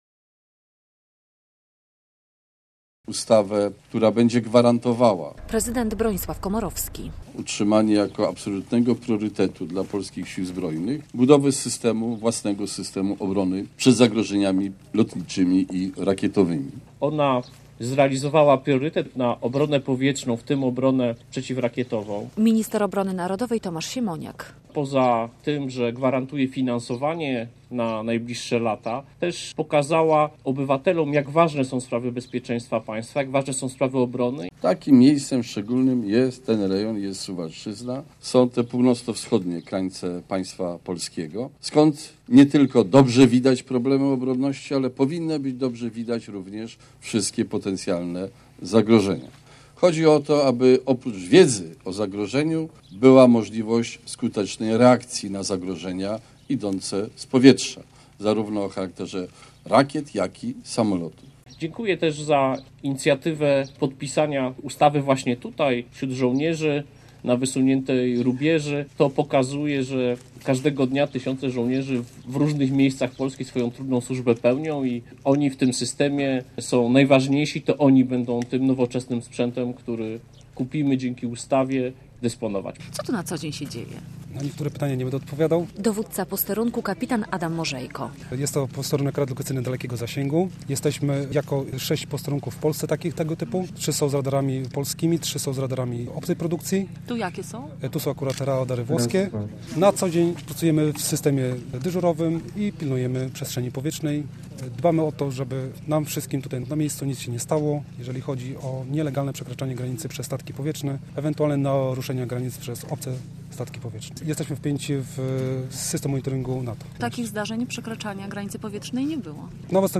Prezydent RP w posterunku radiolokacyjnym dalekiego zasięgu - relacja